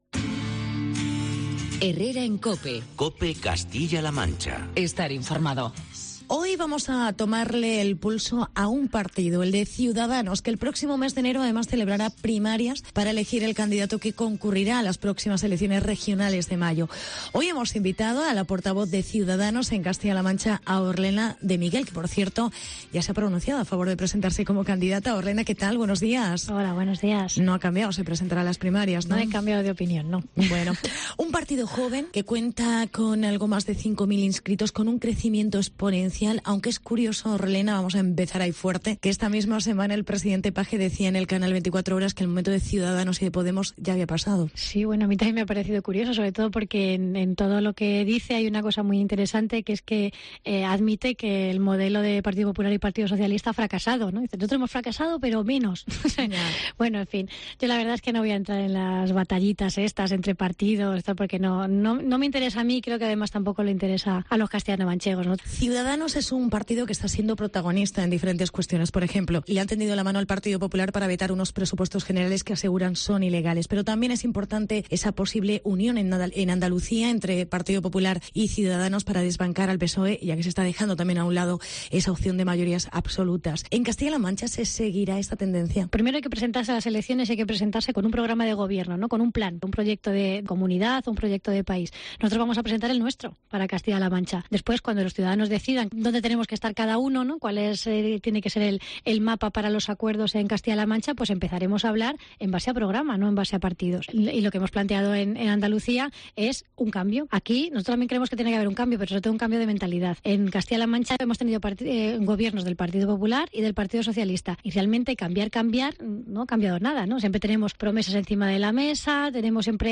Entrevista con Orlena de Miguel. Portavoz de Ciudadanos en CLM